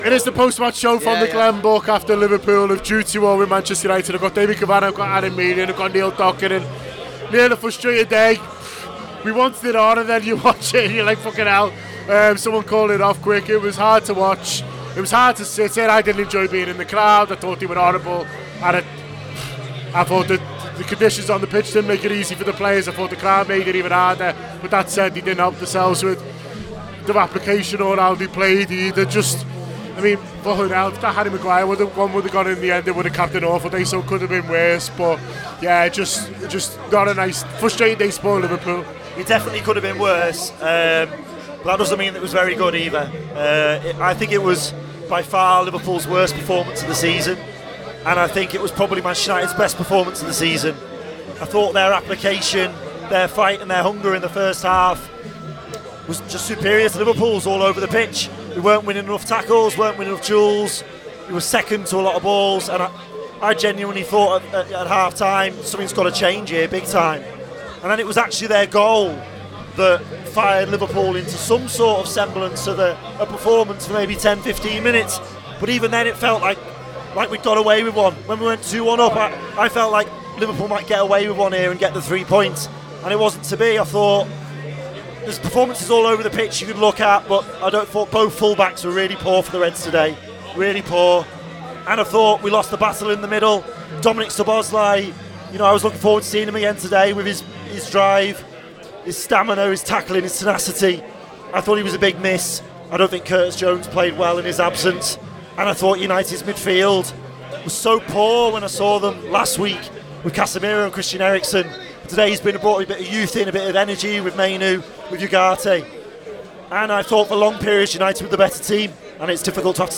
Below is a clip from the show – subscribe to The Anfield Wrap for more reaction to Liverpool 2 Manchester United 2…